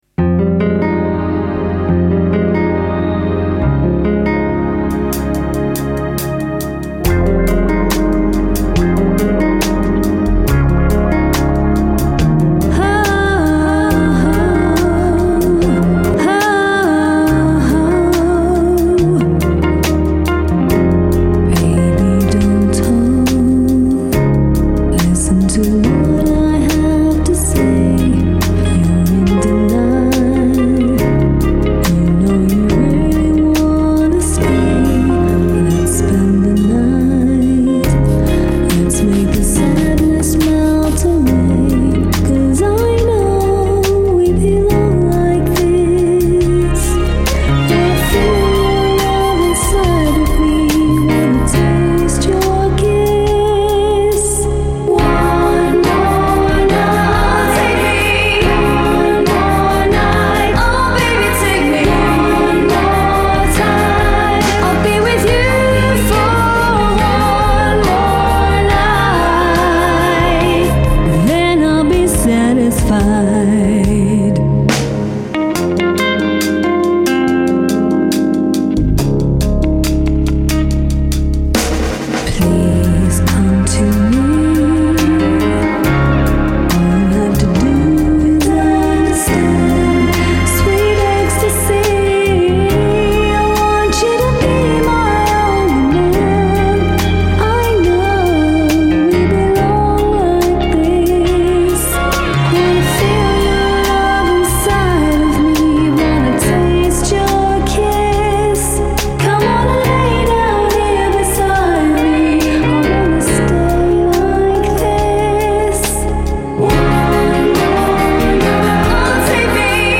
A gentle intimate & seductive song...Real Strings too;-))